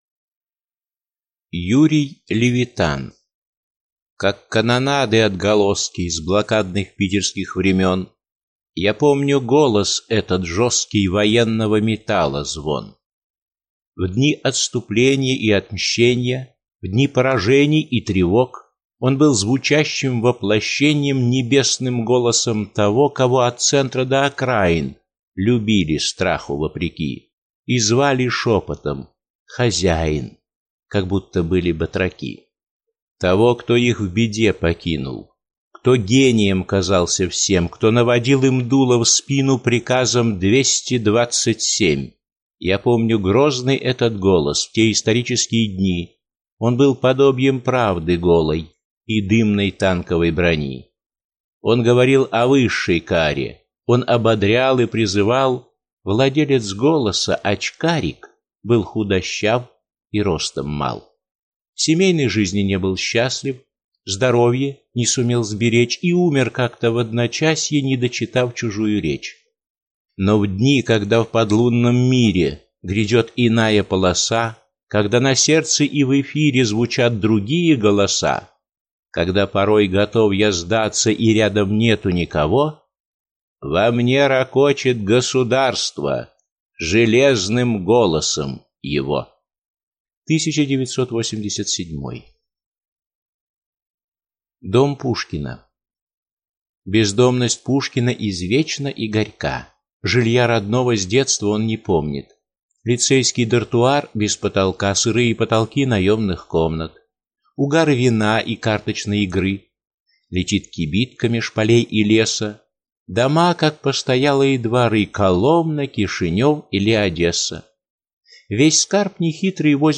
Аудиокнига Стихи и песни (сборник) | Библиотека аудиокниг